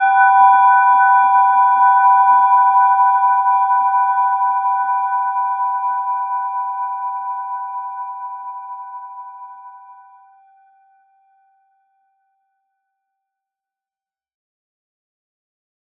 Gentle-Metallic-2-B5-mf.wav